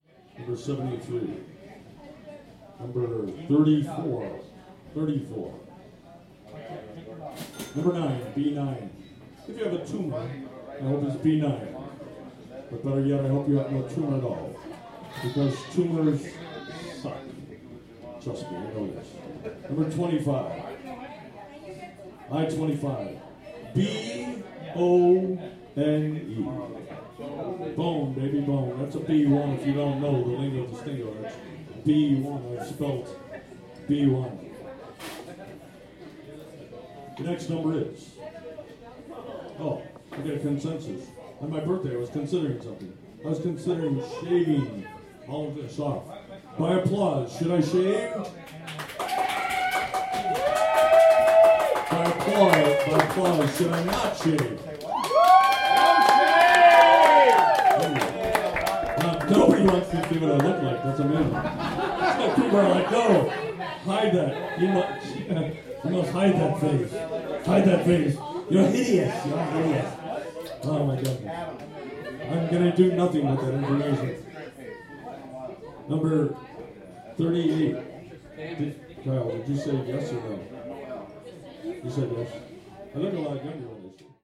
Des boules numérotées dans un gros pot en plastique, un micro et une enceinte qui crache, des grilles en carton, des lots improbables, et tous les désœuvrés du coin repartent avec des chips au fromage et un grand sourire.